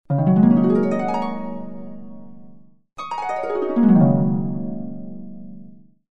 Звуки арфы
Знаменитый переливчатый звук арфы с плавным движением тональности вверх и вниз